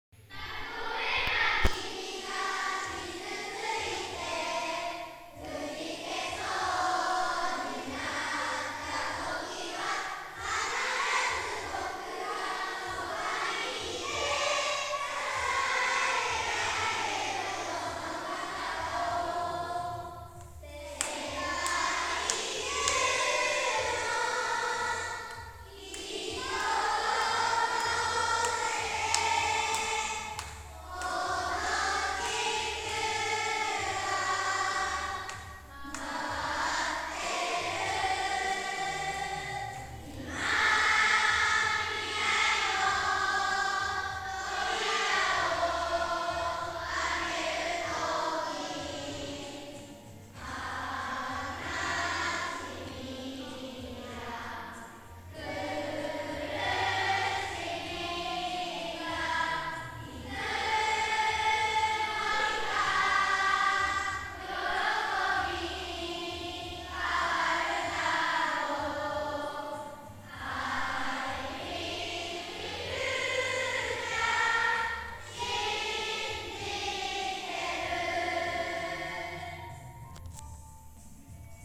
5年生　伊豆高原学園移動教室特集